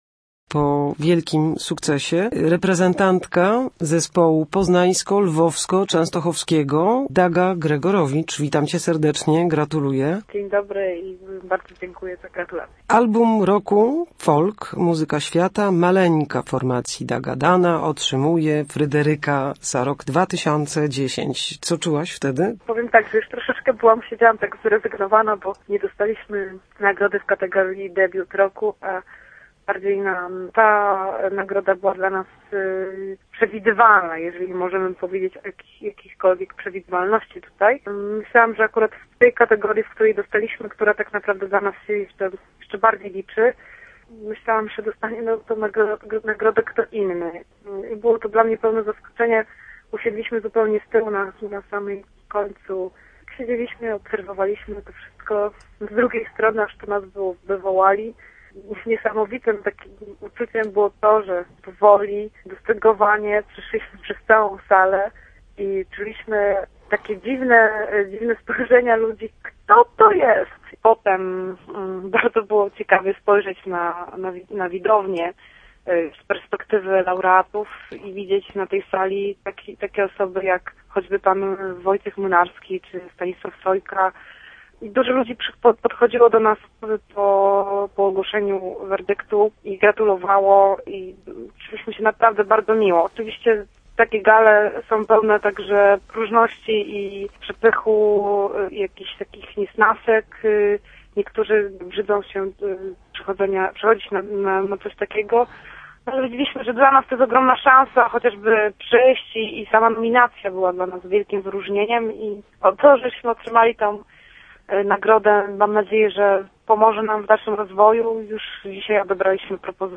Dagadana - reportaż i rozmowa